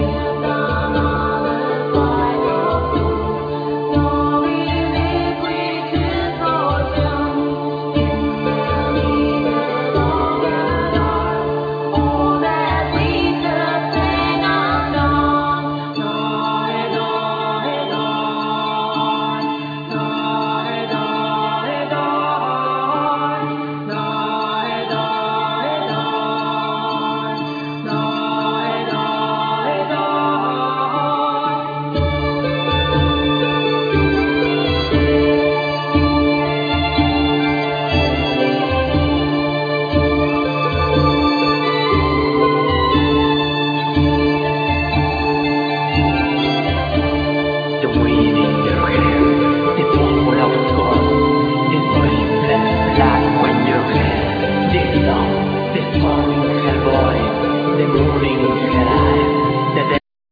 Vocal, Percussions
Keyboards, Backing vocal, Drums
Violin, Backing vocal